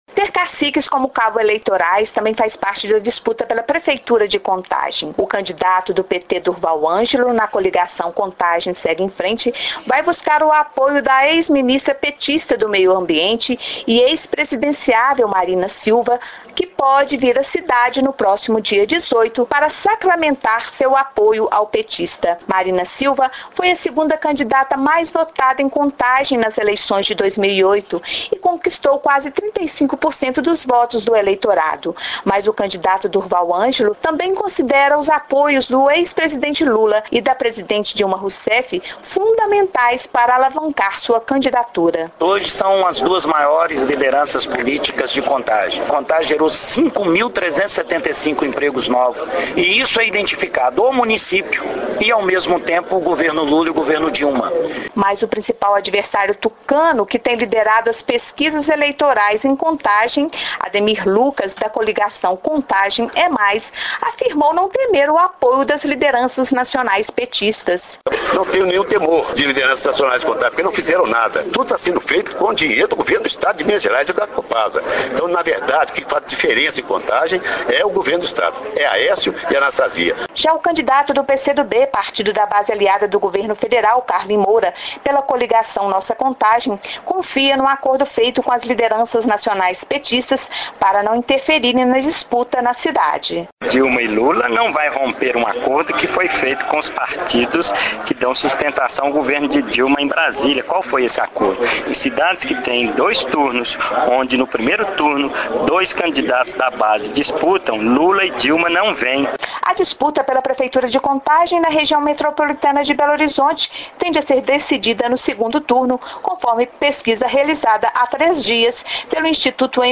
Os custos de se eleger e manter os legislativos municipais são discutidos na terceira reportagem da série "Pra que serve vereador?".